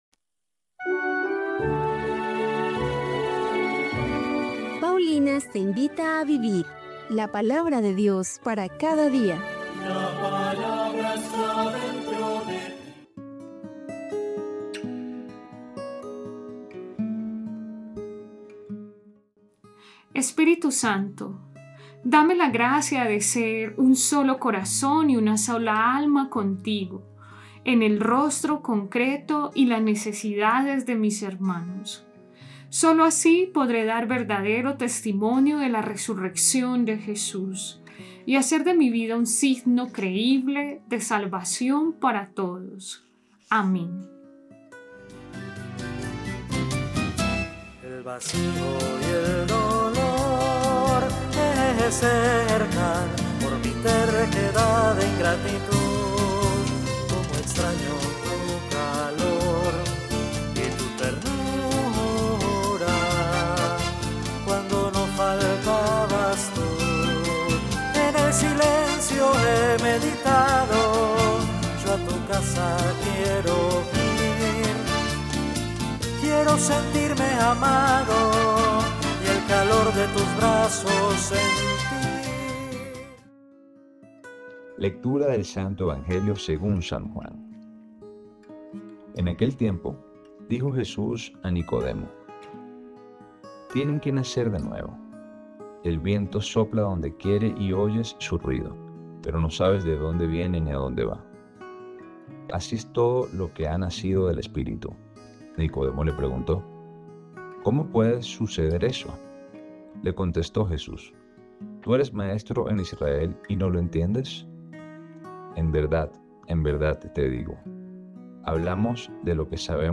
Liturgia diaria